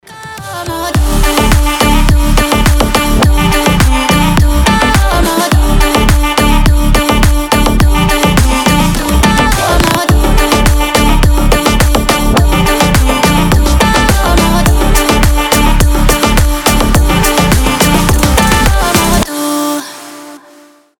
танцевальные , заводные , ритмичные